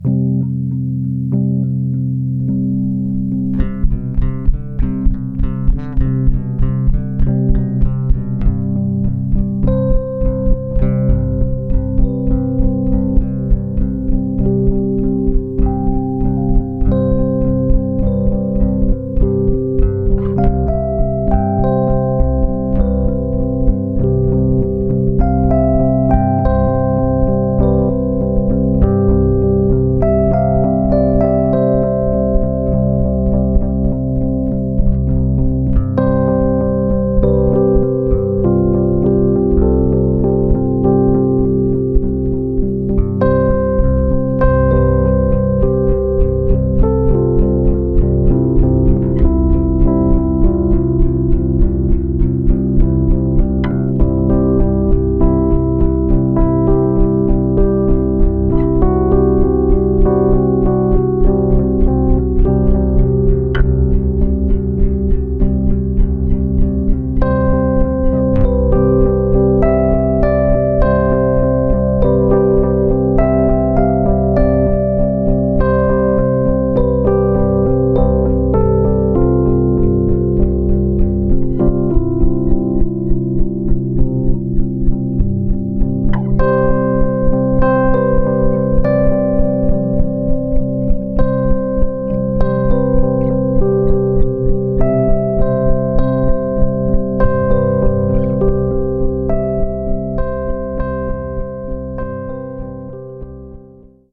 Rhodes and Bass